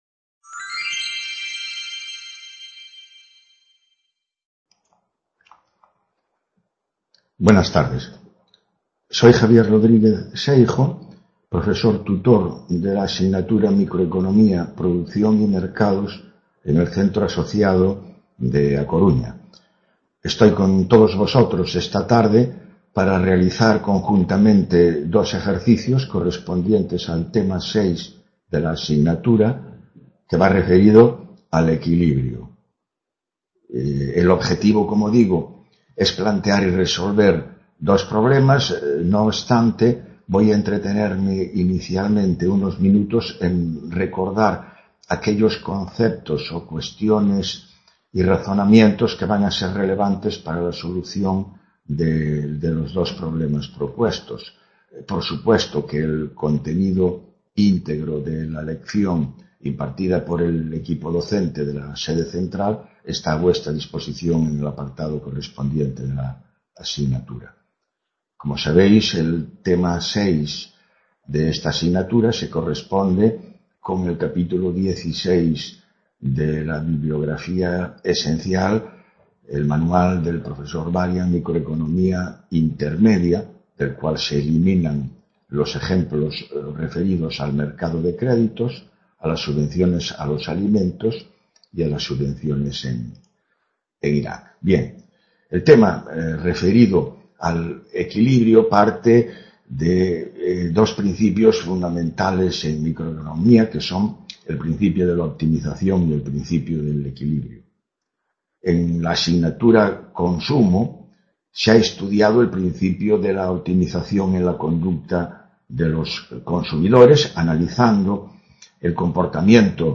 Tutoría Intercampus.